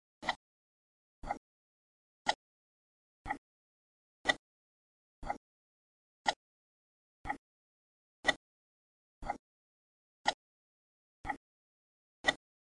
描述：没有环境噪音的挂钟录音
Tag: 挂钟 蜱滴答 时间 滴答 时钟 滴答 拟音